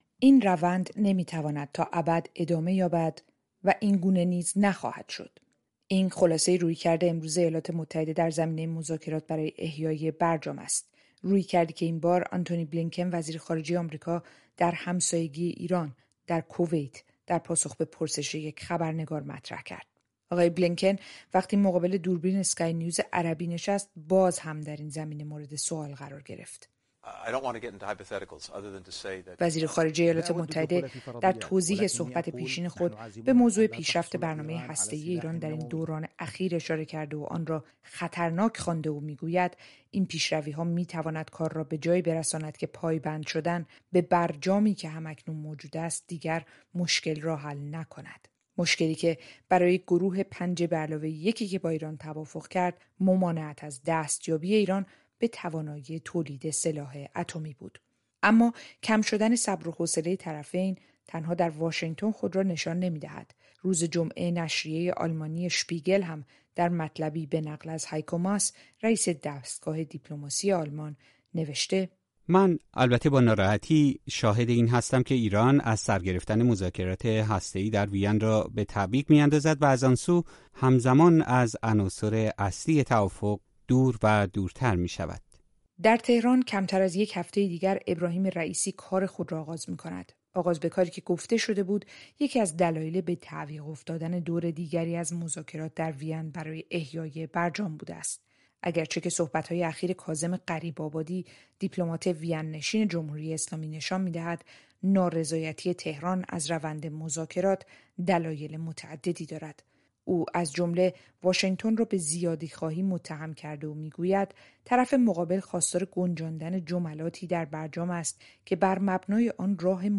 گزارش می‌دهد.